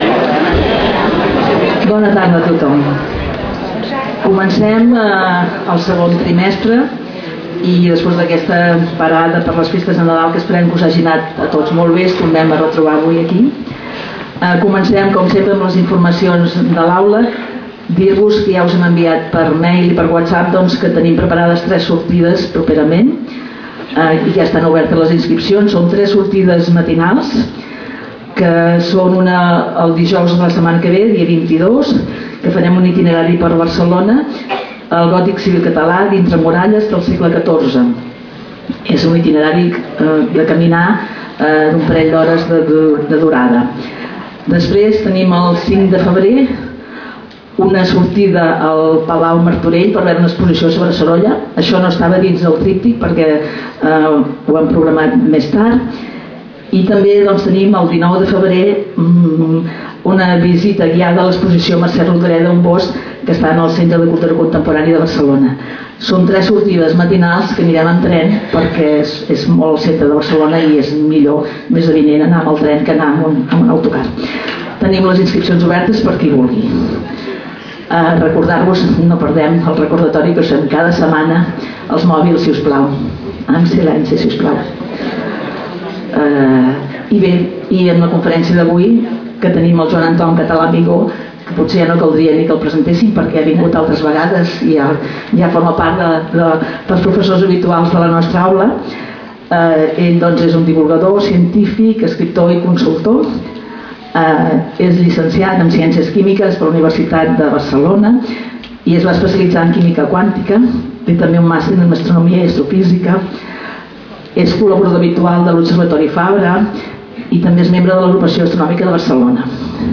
Lloc: Casal de Joventut Seràfica